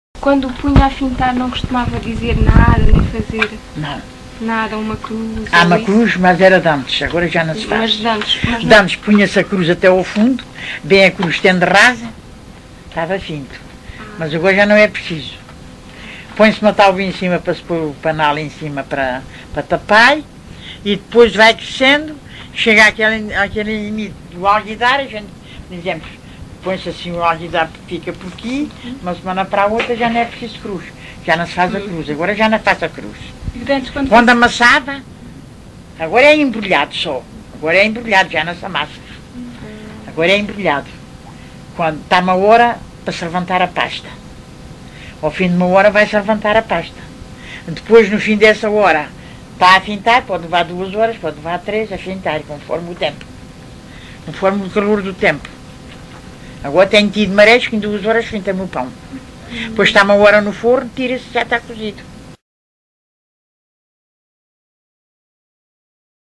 LocalidadeSanta Justa (Coruche, Santarém)